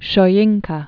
(shô-yĭngkə), Wole Born 1934.